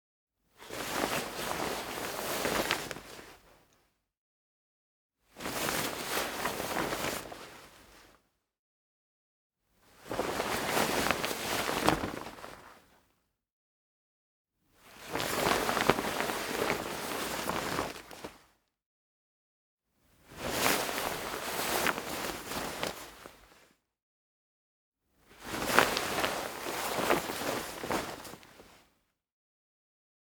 household
Cloth Movement 5